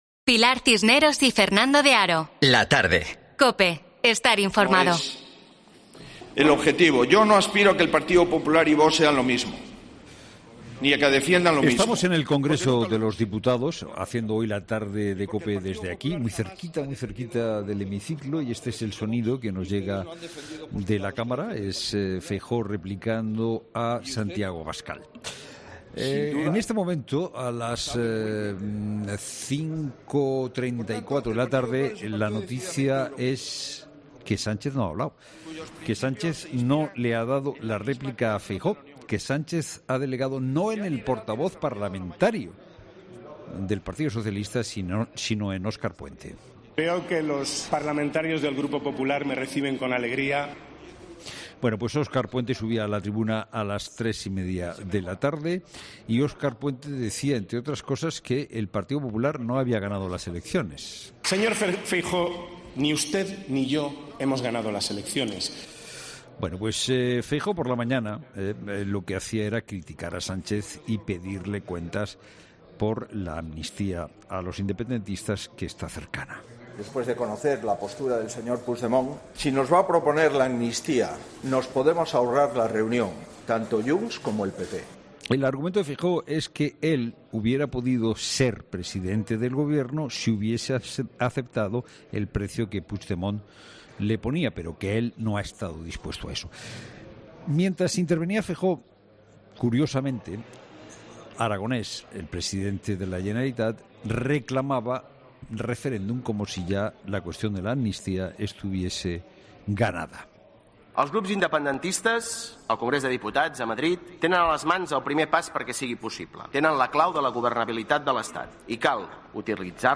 'Herrera en COPE' amanece en el Congreso